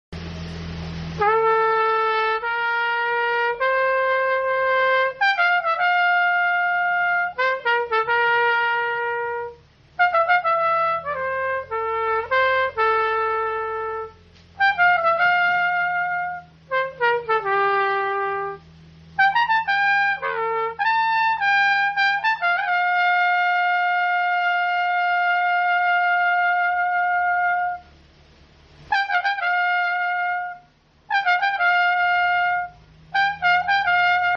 still practicing tho#trumpetplayer